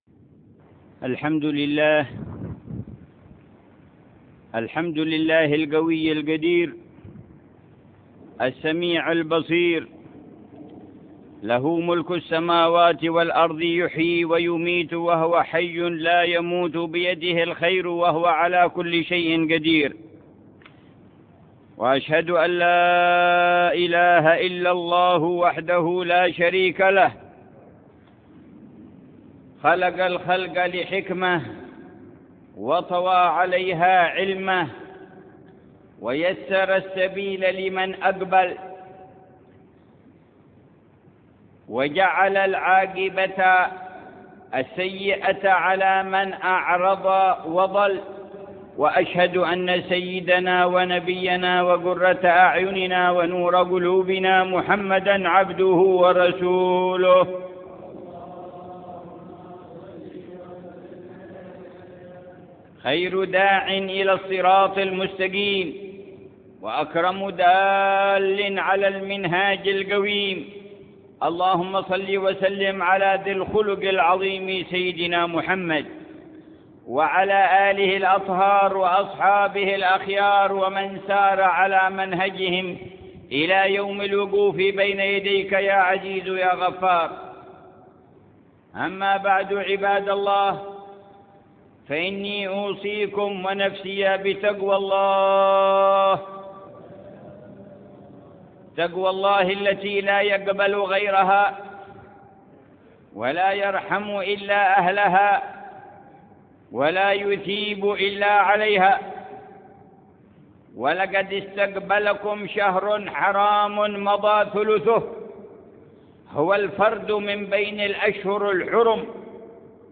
خطبة جمعة للحبيب عمر في الجامع الكبير بمدينة سيئون - حضرموت تاريخ 10 رجب 1430هـ بعنوان: المناهج والبرمجة بمقتضى الإيمان.